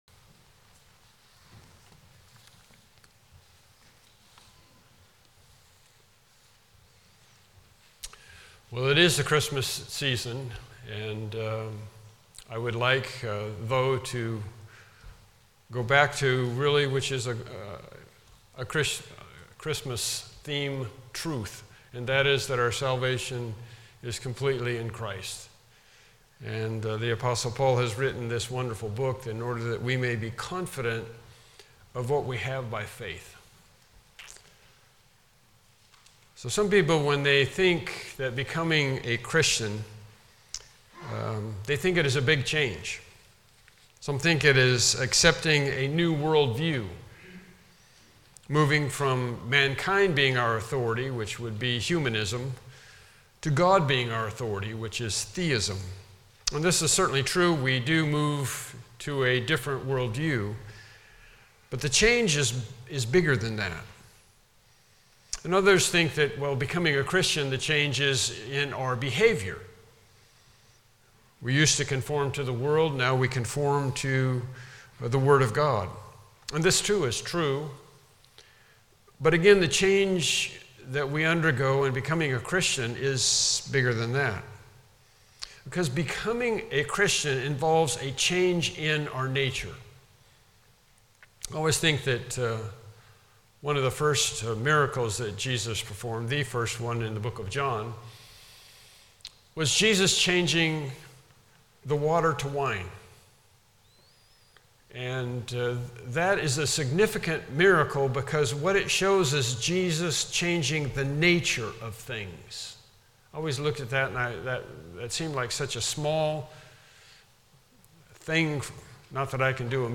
Passage: Galatians 3:26-29 Service Type: Morning Worship Service « Lesson 16